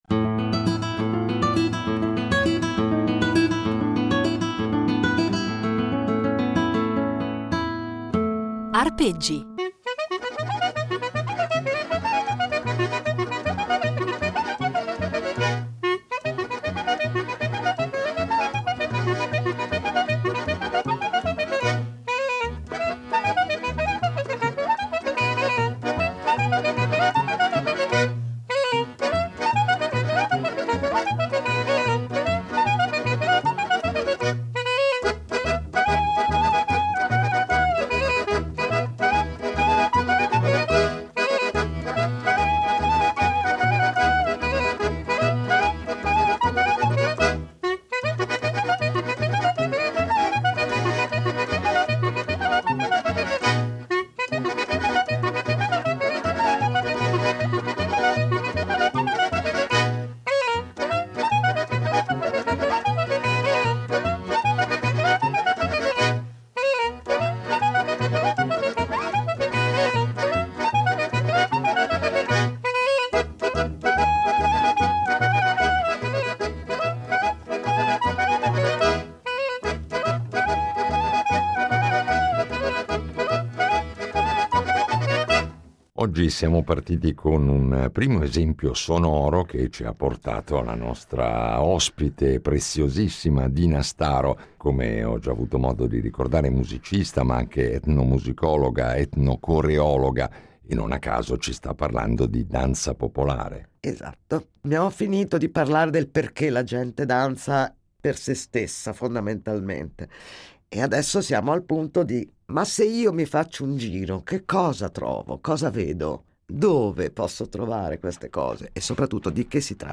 Un itinerario sonoro ricco di materiale inedito, registrato sul campo e negli anni da lei stessa, e illuminato da esempi che ci propone dal vivo, con la sua voce e il suo violino e accompagnata dalle percussioni di